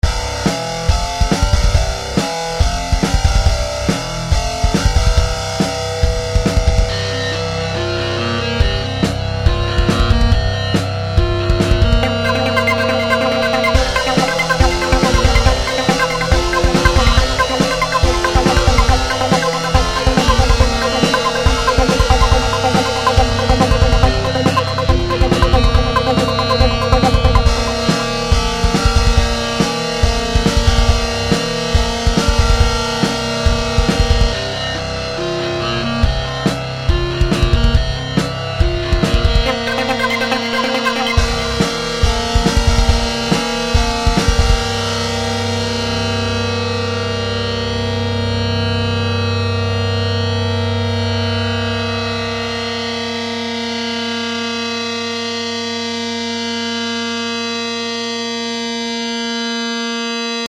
I bought a guitar simulator for FL Studio, and did roughly what you’d expect from that combination.